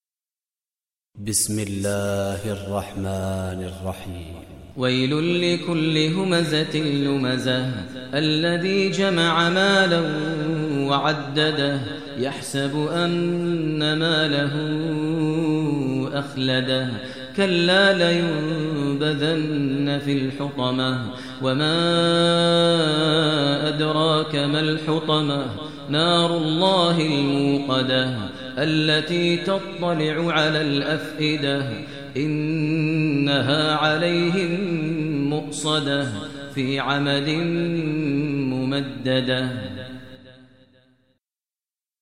Surah Humazah Recitation by Maher Mueaqly
Surah Humazah, listen online mp3 tilawat / recitation in Arabic recited by Imam e Kaaba Sheikh Maher al Mueaqly.